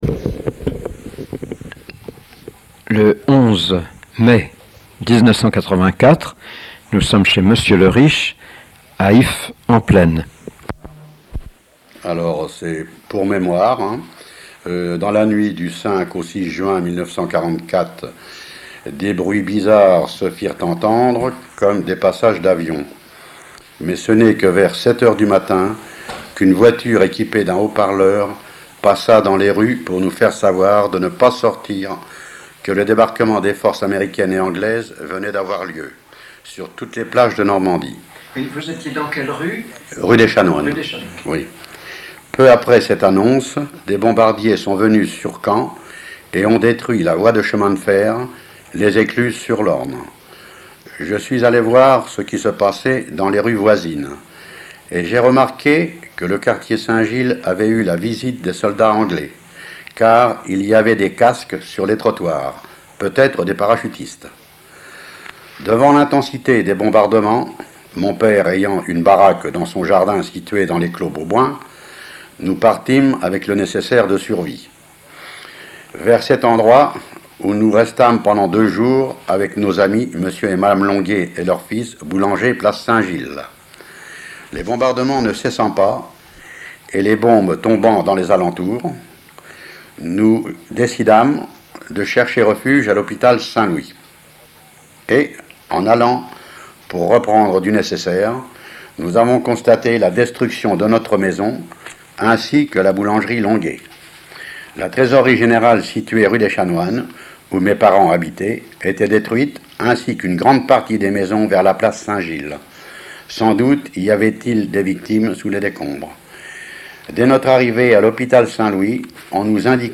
Cette interview est issue du fonds d'enregistrements de témoignages oraux relatifs à la Seconde Guerre mondiale conservés et archivés au Mémorial de Caen, que le musée a gracieusement mis à la disposition de la Maison de la Recherche en Sciences Humaines dans le cadre du projet de recherche Mémoires de Guerre.